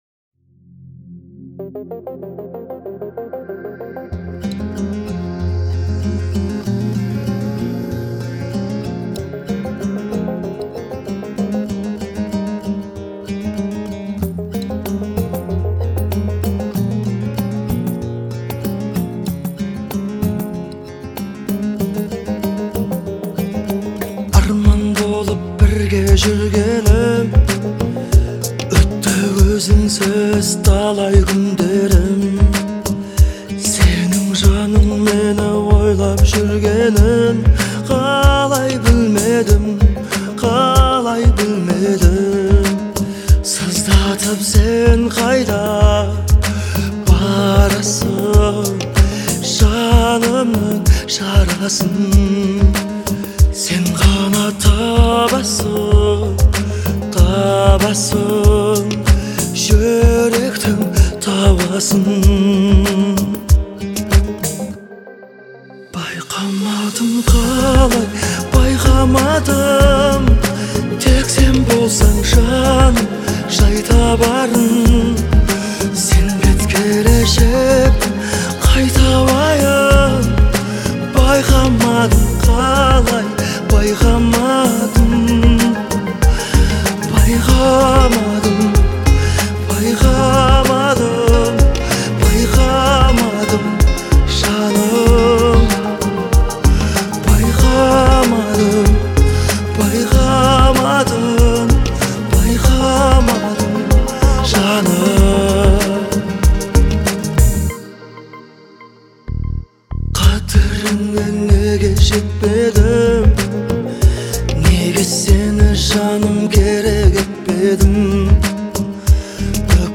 относится к жанру поп и R&B